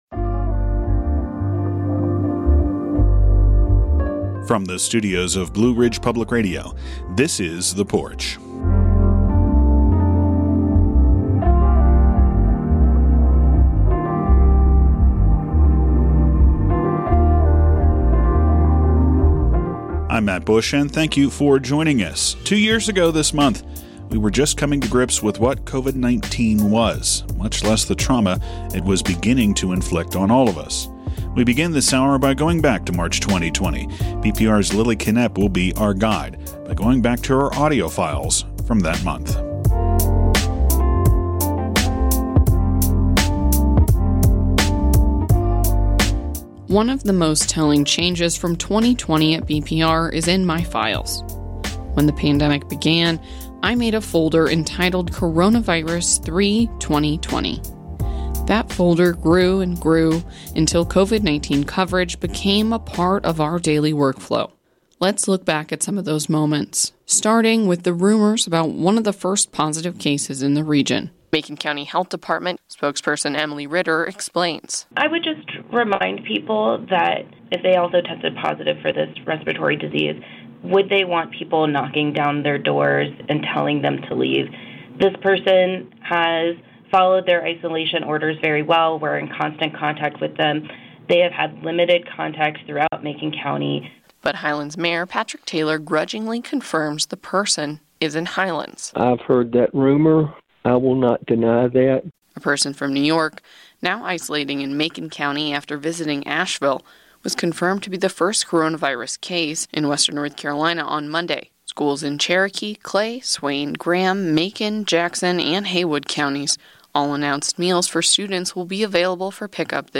The BPR news team marks the two-year anniversary of the COVID-19 pandemic, and talks in-depth about Western North Carolina politics